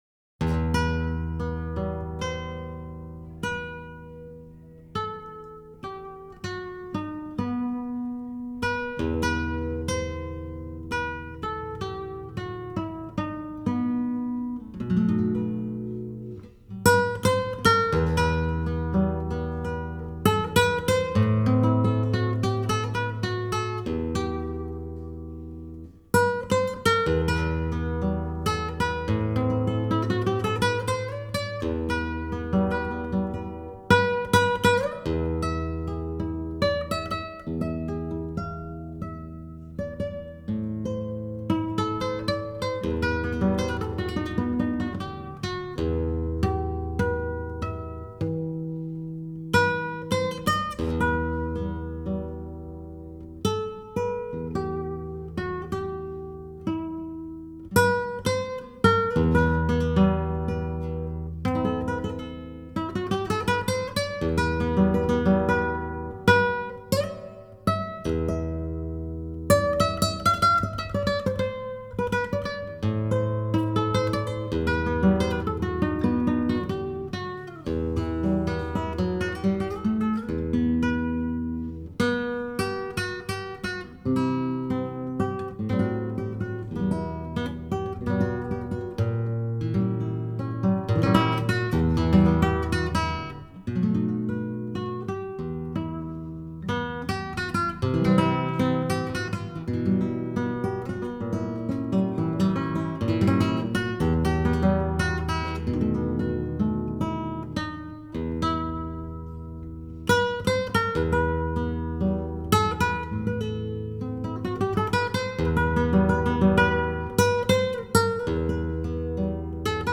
NICARAGUAN MUSIC
is a melancholy number that runs just over three minutes.